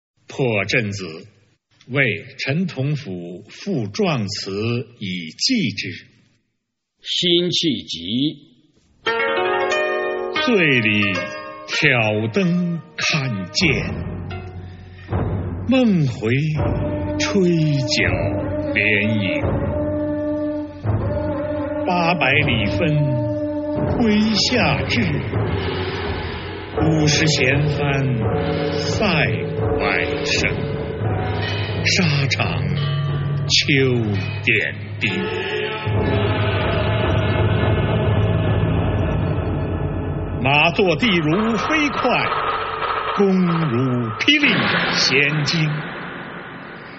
九年级语文下册12 词四首《破阵子·为陈同甫赋壮词以寄之》男声激情朗诵（音频素材）